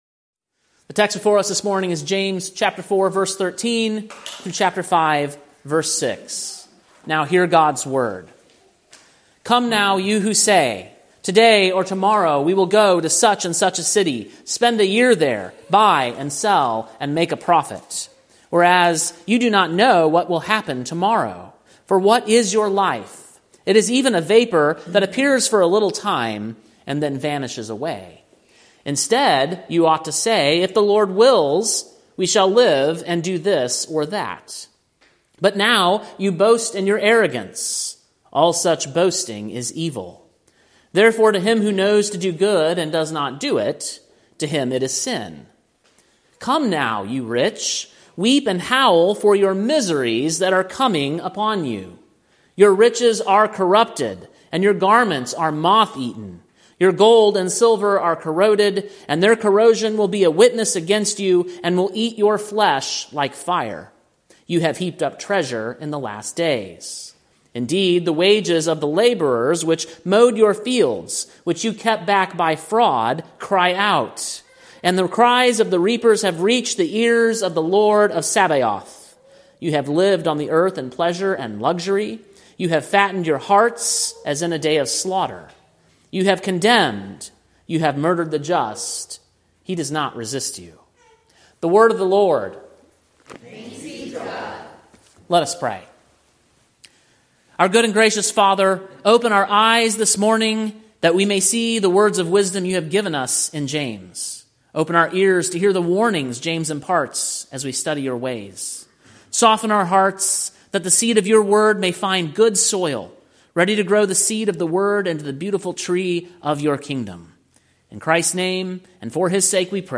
Sermon preached on March 23, 2025, at King’s Cross Reformed, Columbia, TN.